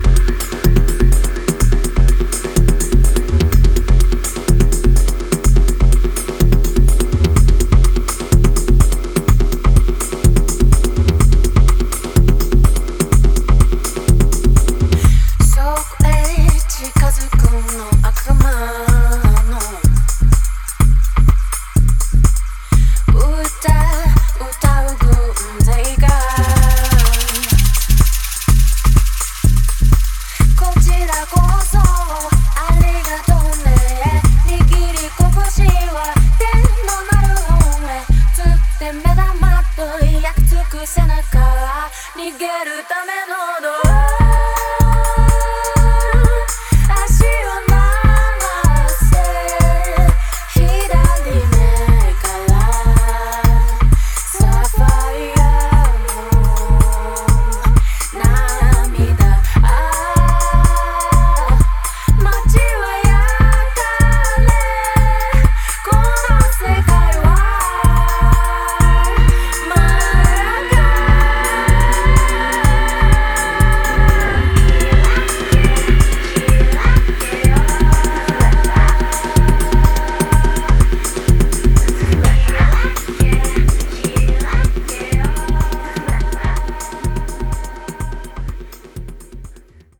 アシッドテクノ、ダブステップなどの影響を感じさせてくれる